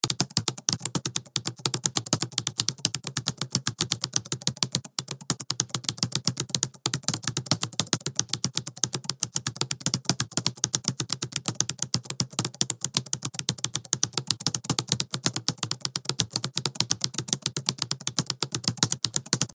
جلوه های صوتی
دانلود صدای تایپ 9 از ساعد نیوز با لینک مستقیم و کیفیت بالا